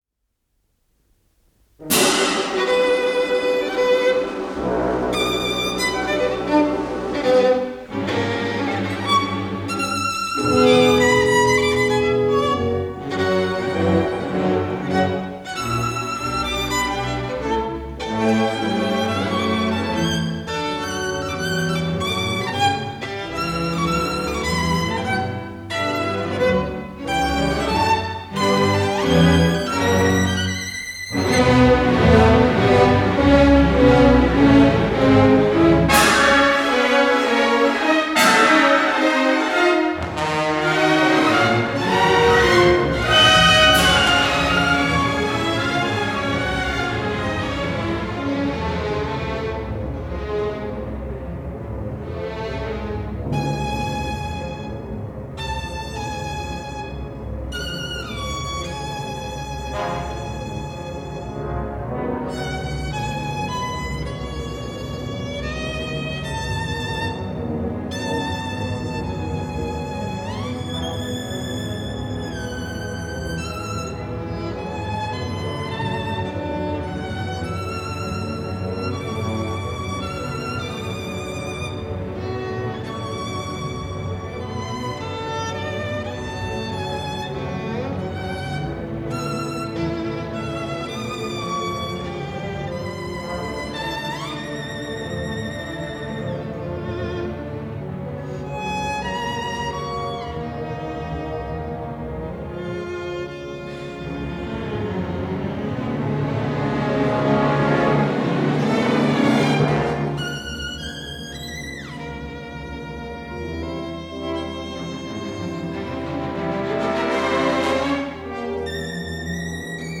Legendary German violinist Gerhard Taschner with conductor Gustav König and the Hessian Radio Symphony in a broadcast performance of Hans Pfitzner’s Violin Concerto – recorded on April 28, 1955.